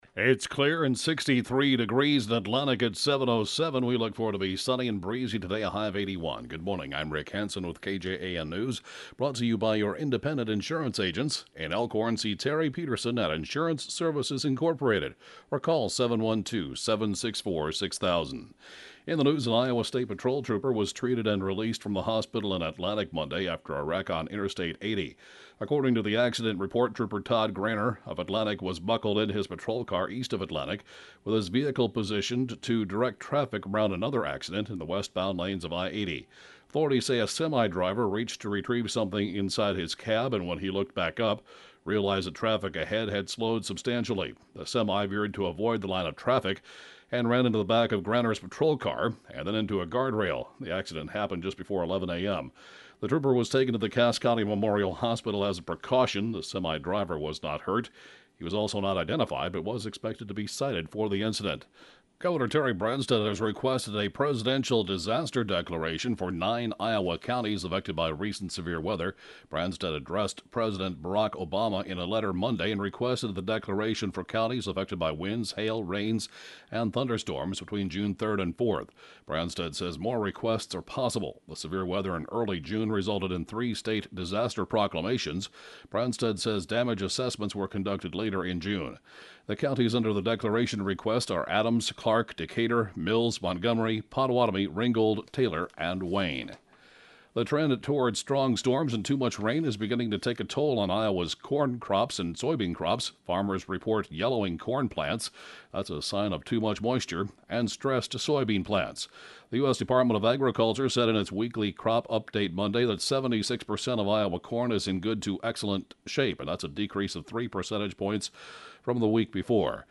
(Podcast) 7-a.m. News & funeral report, Tue. July 8th 2014